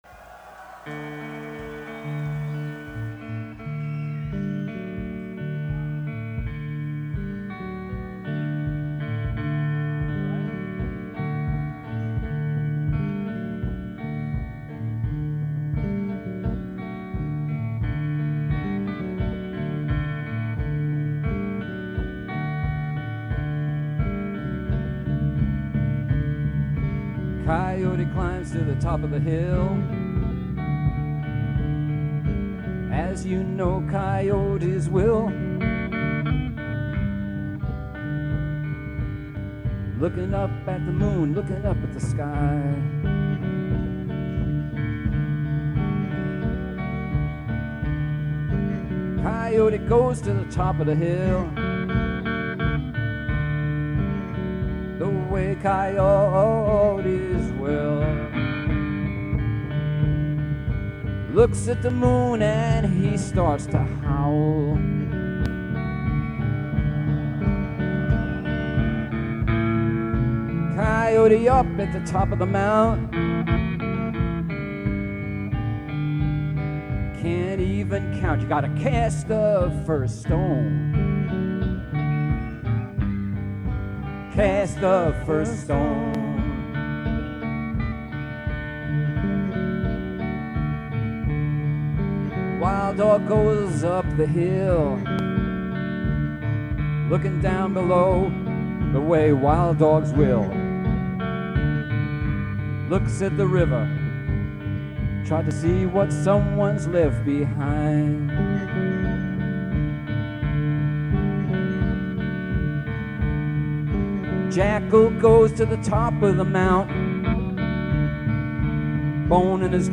guitar, vocals
bs, keyboards, viola, vocals
drums, vocals